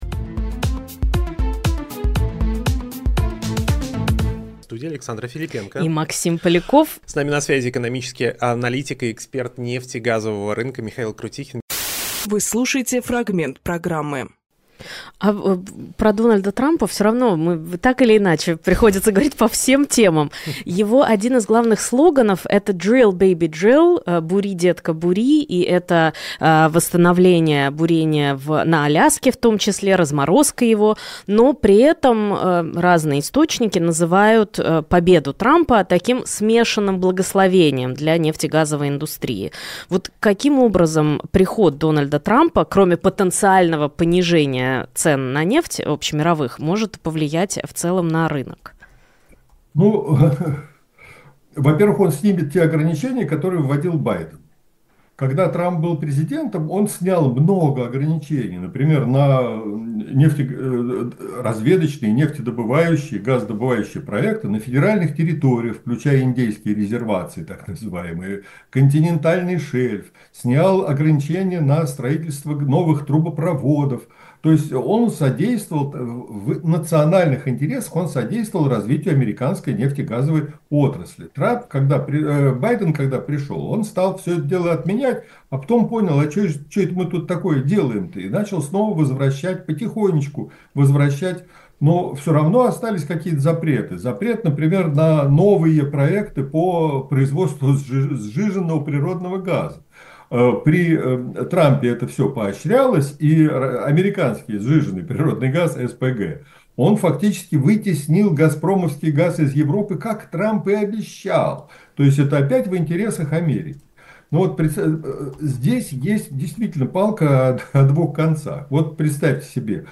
Фрагмент эфира от 12 ноября